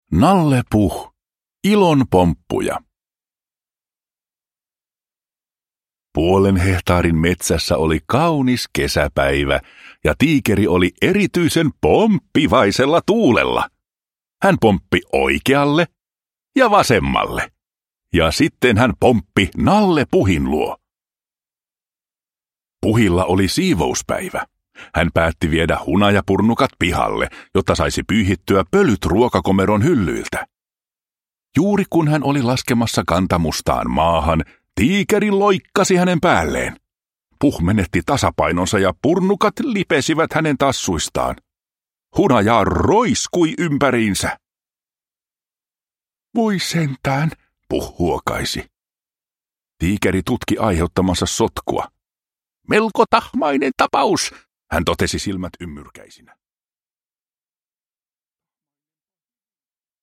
Nalle Puh. Ilon pomppuja – Ljudbok – Laddas ner